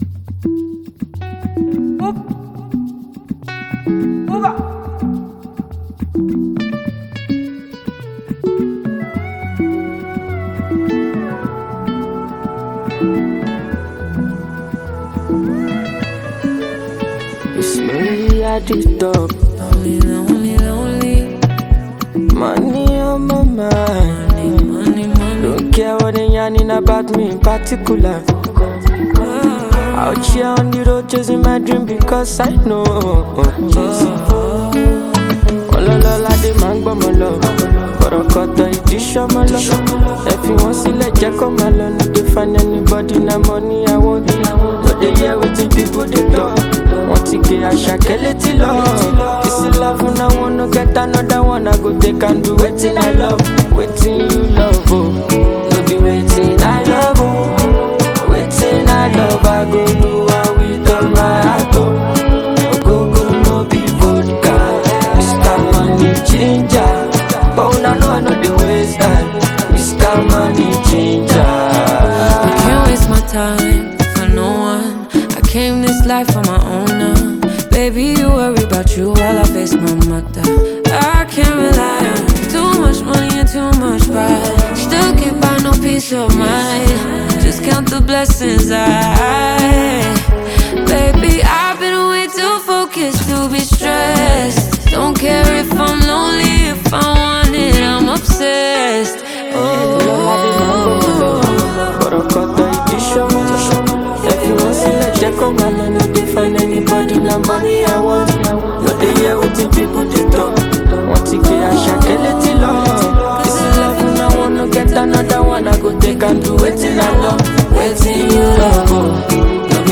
the singer features American R&B singer and songwriter